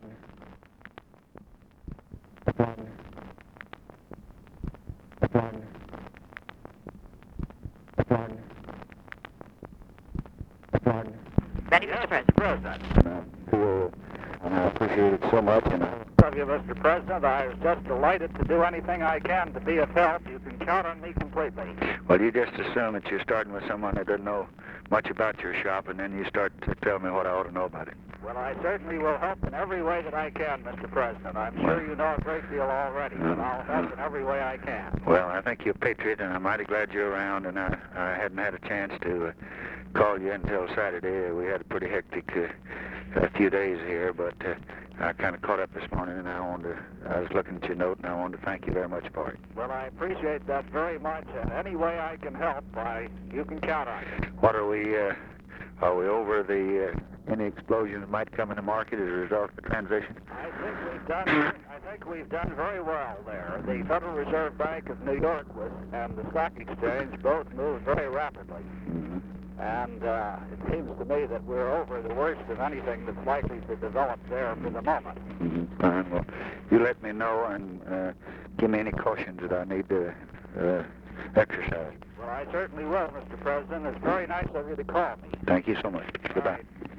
Secret White House Tapes | Lyndon B. Johnson Presidency Conversation with WILLIAM MCC.